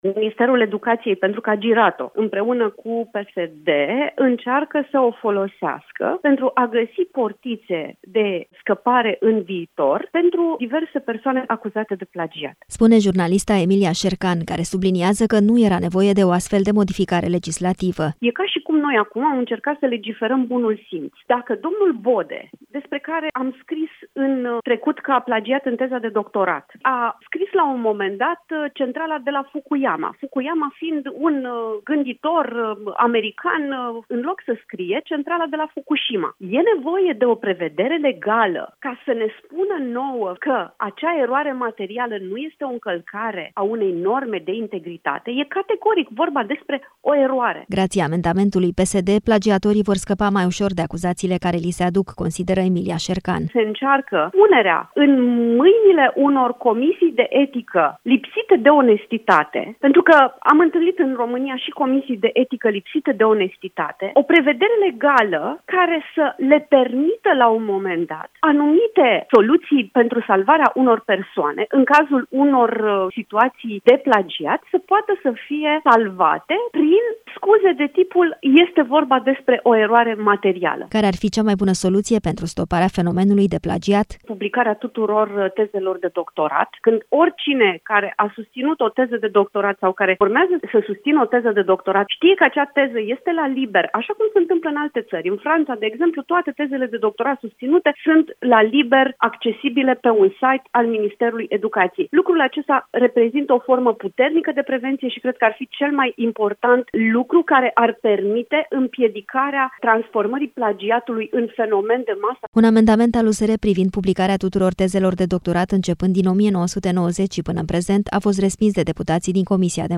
Reacția sa la Europa FM vine după ce ieri, deputații din comisia de învățământ au aprobat un amendament al PSD care prevede, între altele, că erorile materiale de citare nu vor fi considerate plagiat.